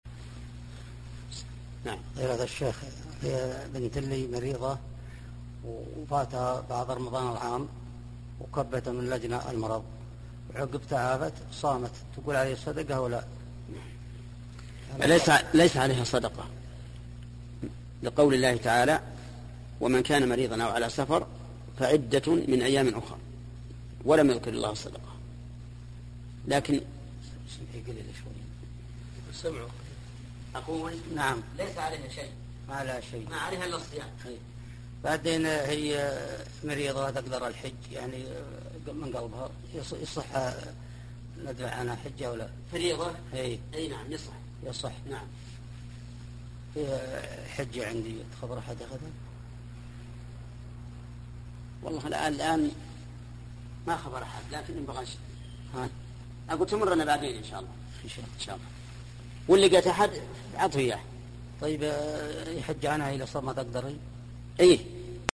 المصدر: سلسلة لقاءات الباب المفتوح > لقاء الباب المفتوح [22]